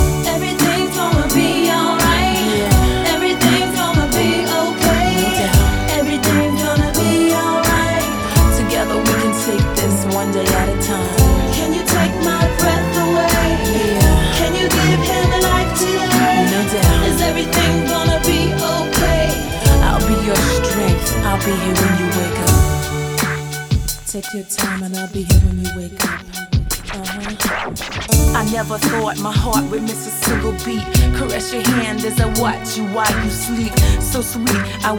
Жанр: Поп музыка / Танцевальные / Хаус